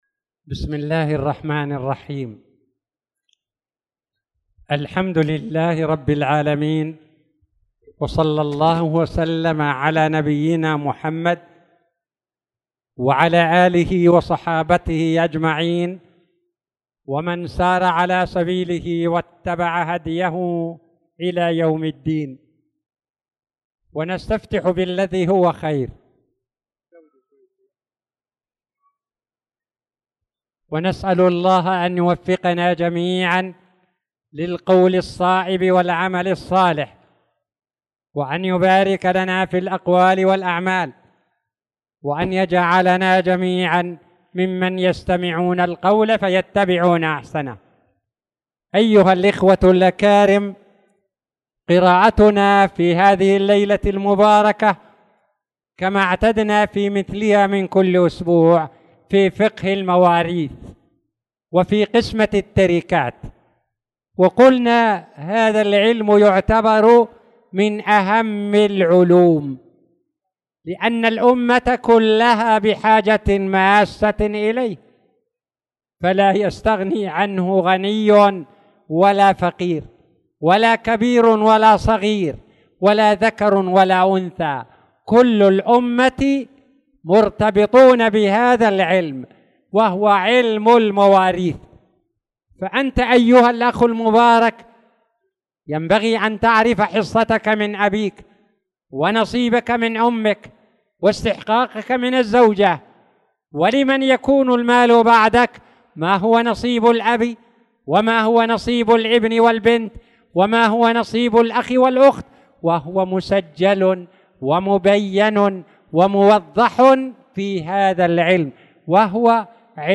تاريخ النشر ٢٠ شوال ١٤٣٧ هـ المكان: المسجد الحرام الشيخ